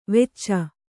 ♪ vecca